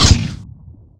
m41a-fire2.wav